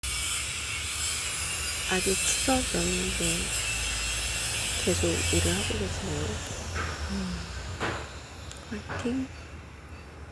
연휴 중에도 일하는 소리가 들려온다.
휴일도 반납하고 열심히 기계를 돌리는 어느 가장을 보며
<문래동 작업장 소리 채집>